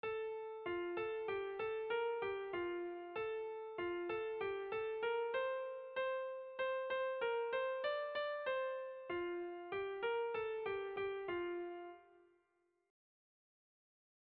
Erromantzea
AB